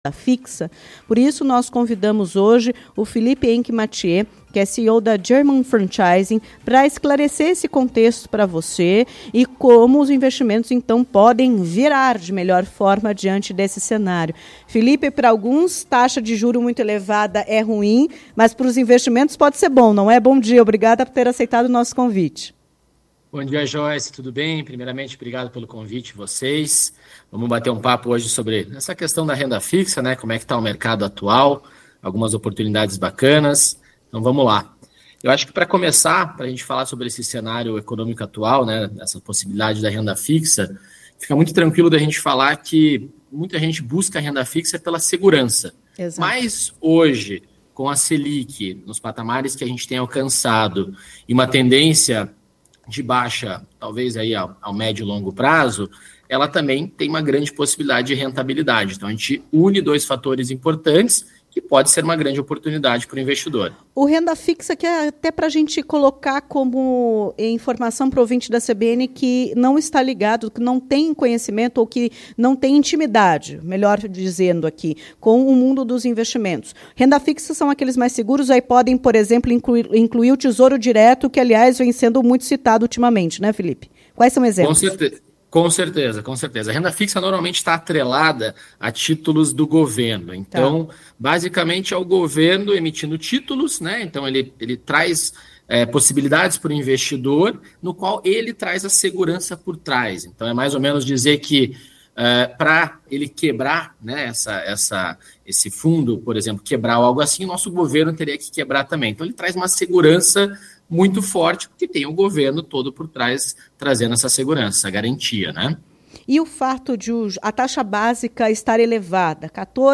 Em entrevista à CBN Curitiba